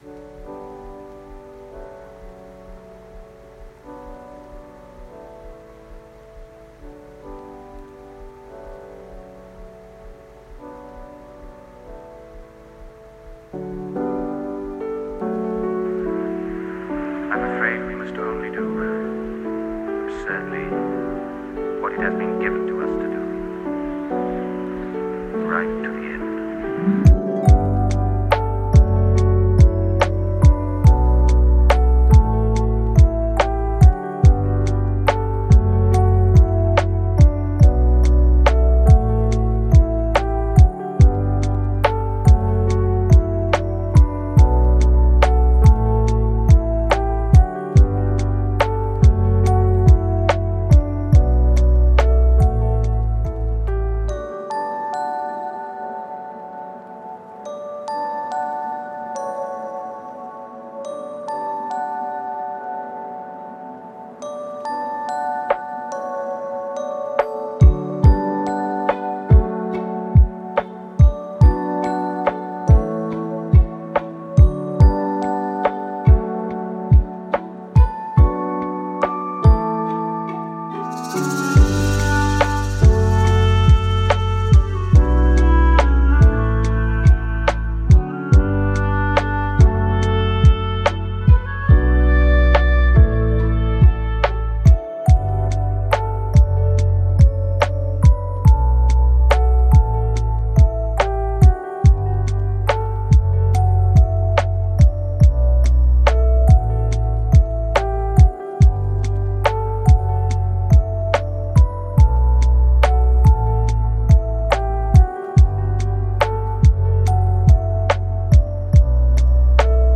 موسیقی بی کلام
موسیقی آرام بخش